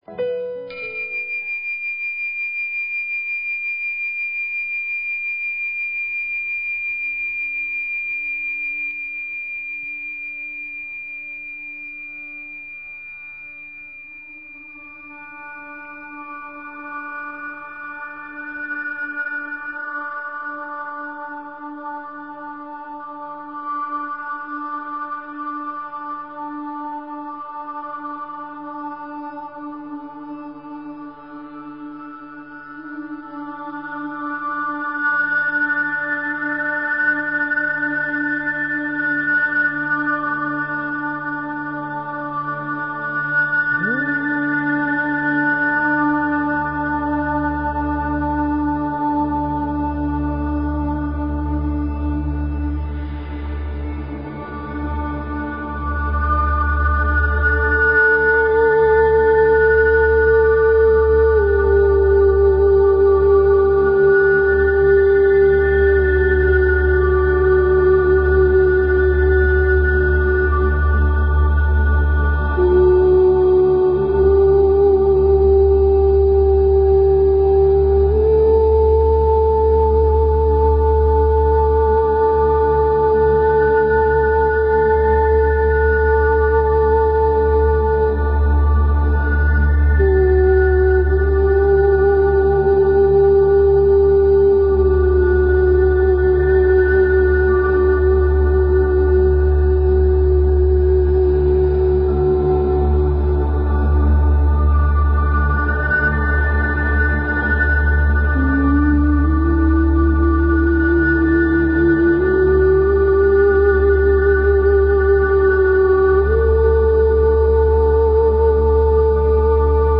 Talk Show Episode, Audio Podcast, Radiance_by_Design and Courtesy of BBS Radio on , show guests , about , categorized as
Radiance By Design is specifically tailored to the energies of each week and your calls dictate our on air discussions.